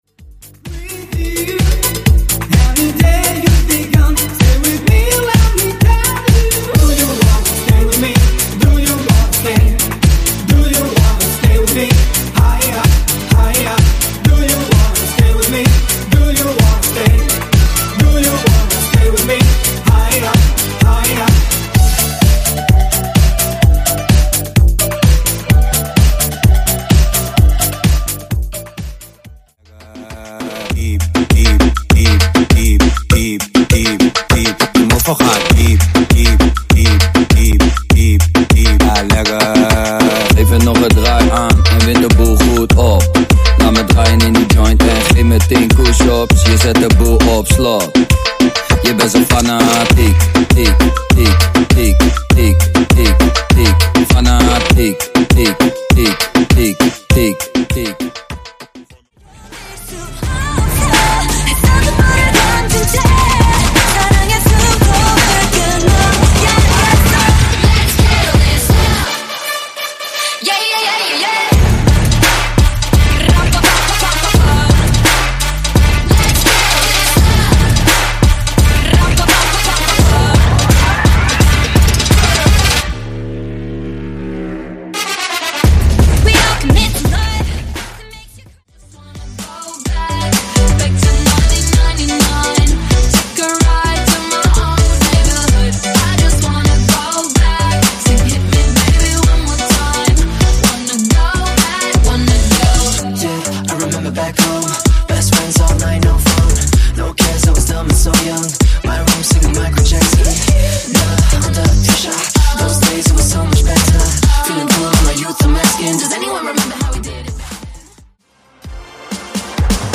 Genres: DANCE , TOP40
Clean BPM: 143 Time